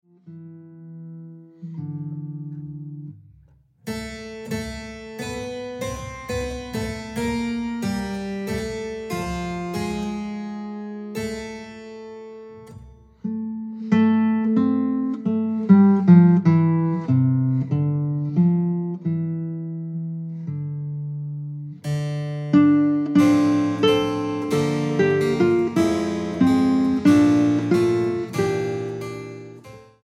guitarra
clavecín
música original para guitarra y clavecín